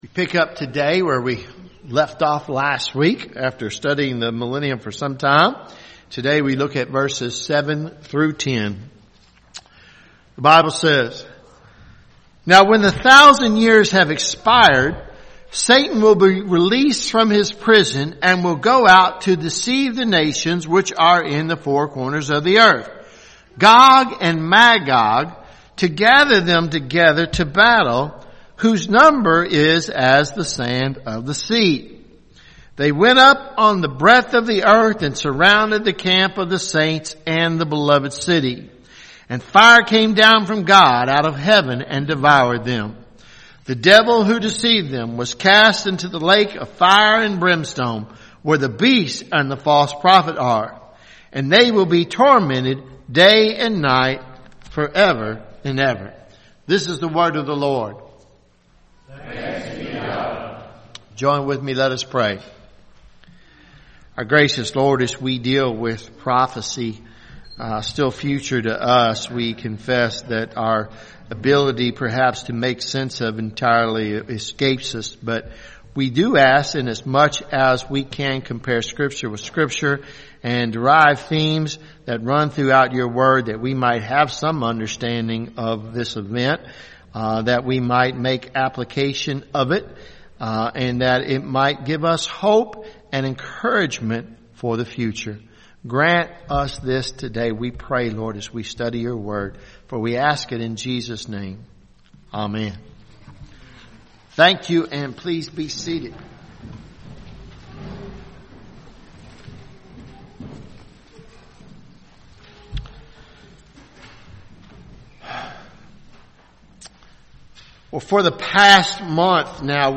Sermons Feb 14 2021 “The Last Battle